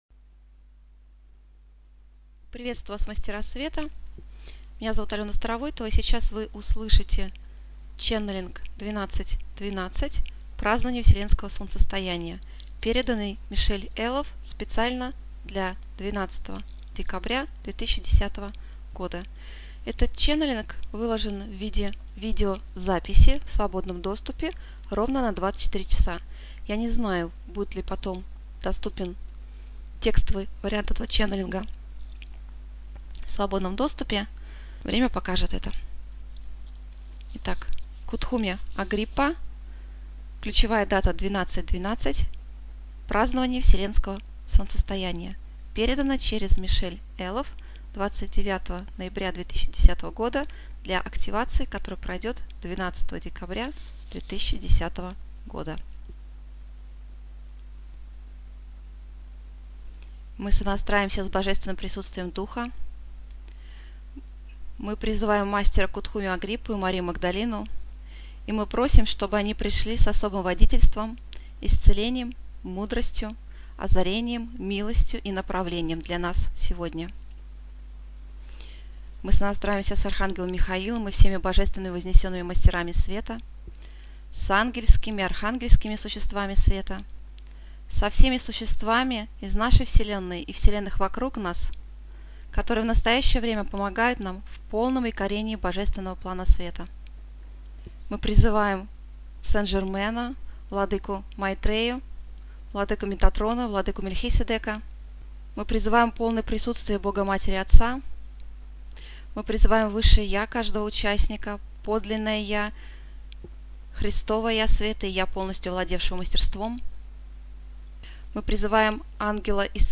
Можно сказать, что эта вспышка совпала с посланием Кутхуми - короля Солнечных Архангелов (прослушать ченнелинг-медитацию).